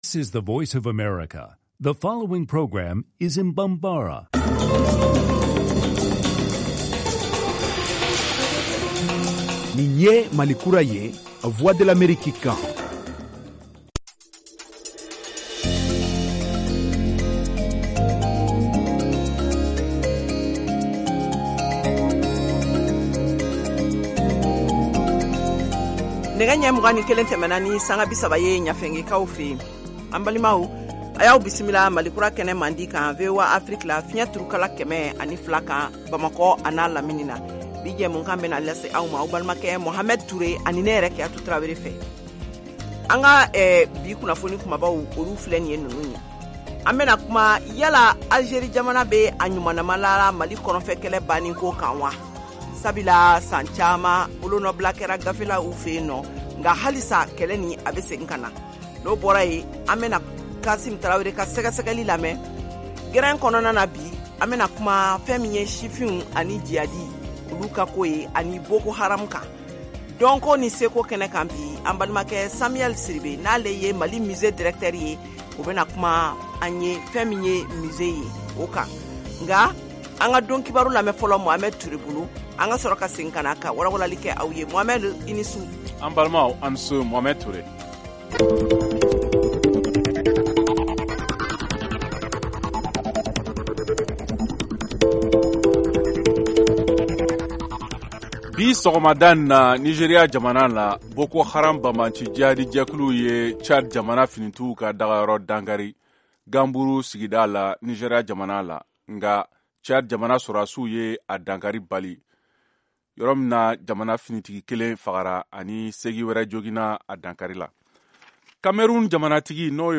en direct de Washington, DC, aux USA.